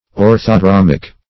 Search Result for " orthodromic" : The Collaborative International Dictionary of English v.0.48: Orthodromic \Or`tho*drom"ic\, a. [Ortho- + Gr.
orthodromic.mp3